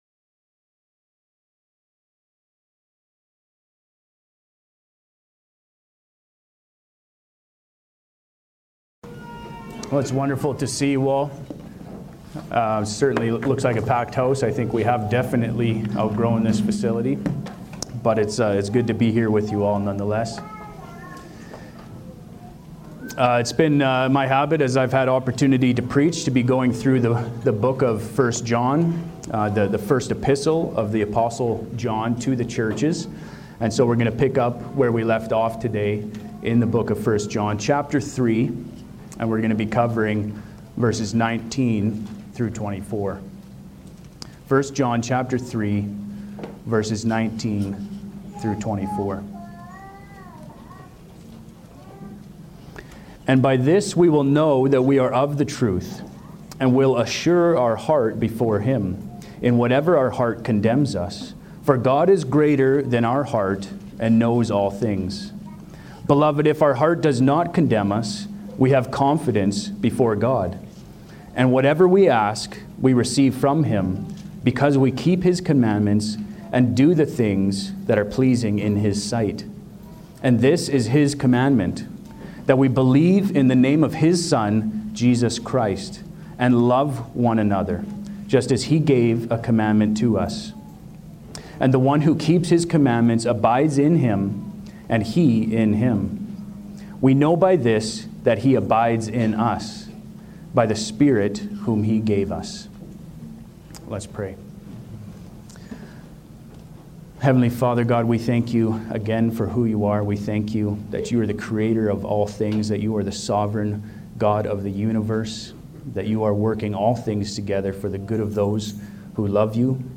Category: Pulpit Sermons